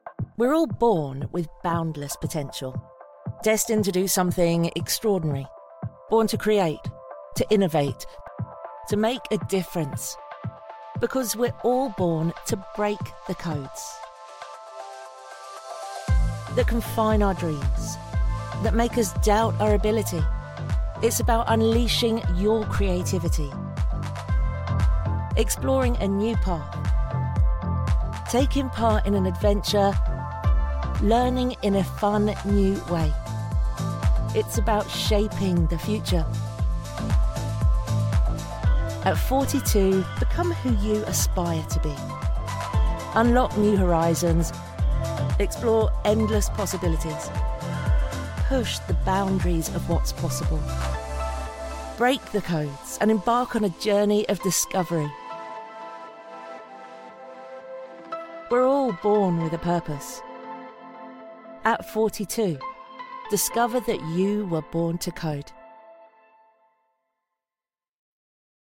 Anglais (britannique)
Vidéos d'entreprise